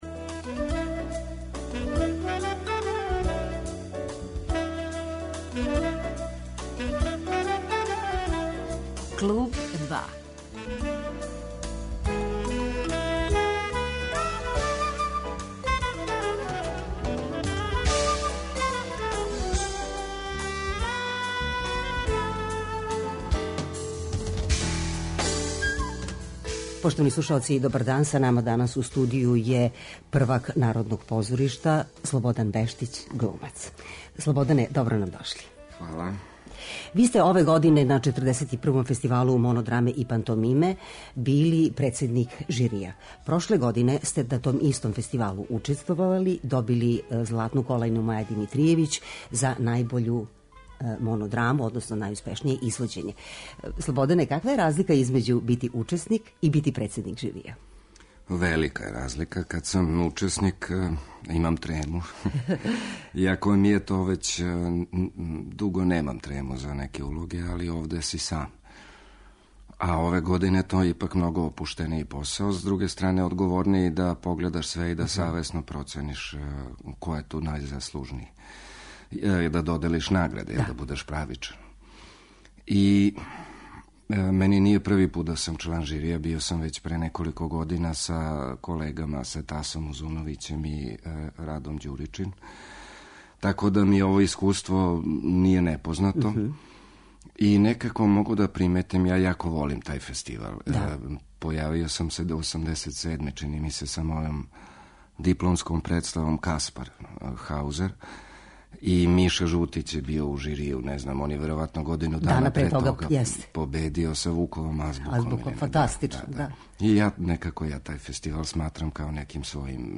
Гост емисије је глумац Слободан Бештић.